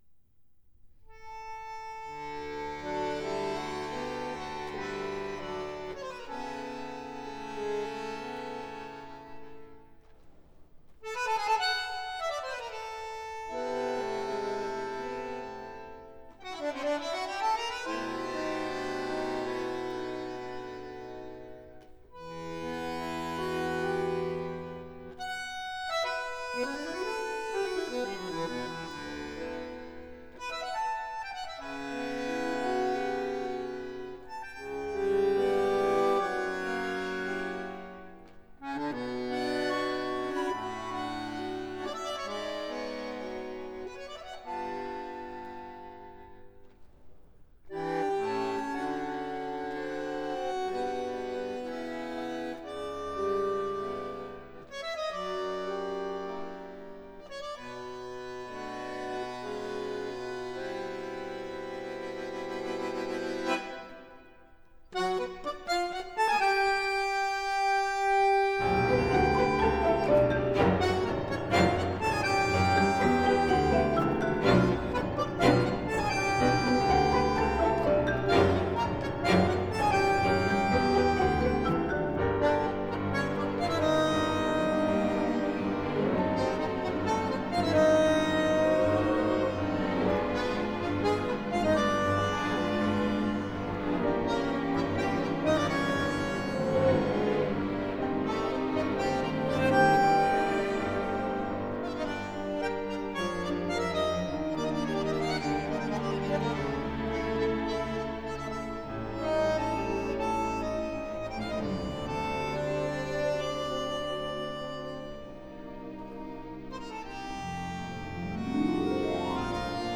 Genre: Tango
Recorded at MCO Hilversum, The Netherlands, April 2002.